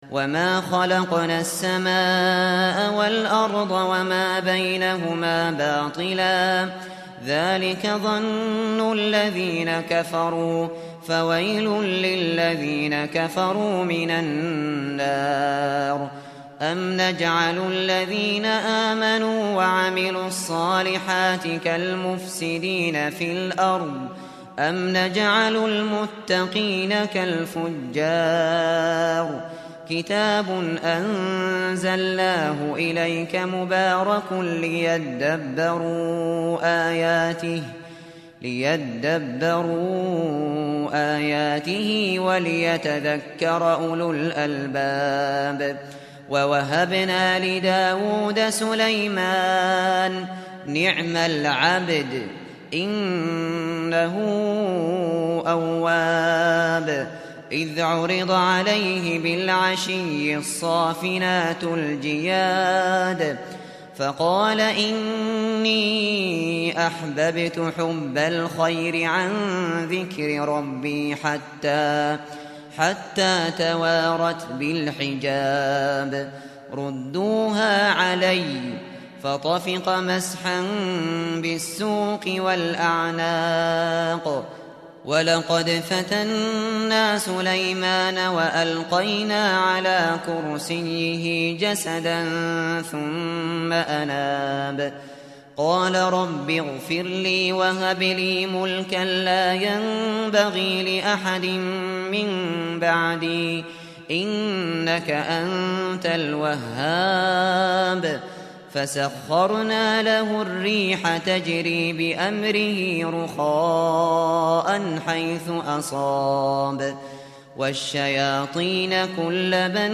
Kur'ân dinlemeye başlamak için bir Hafız seçiniz.